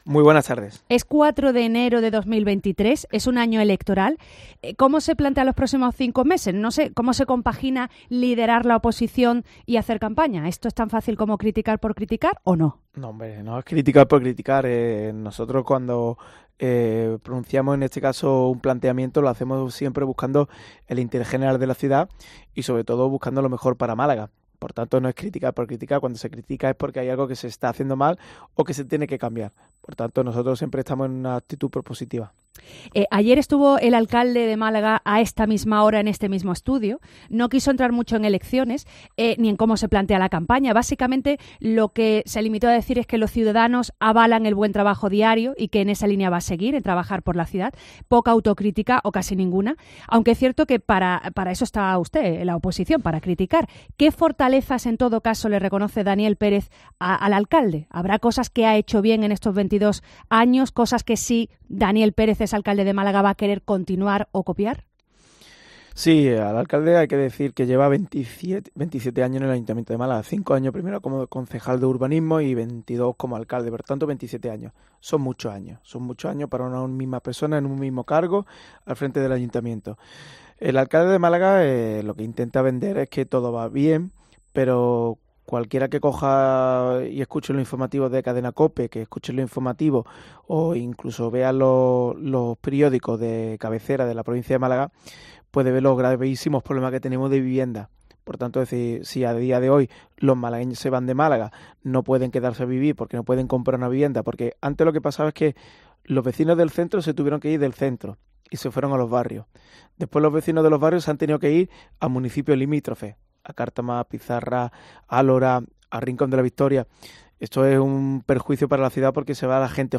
asegura Pérez en una entrevista en los estudios de COPE Málaga en este arranque de año electoral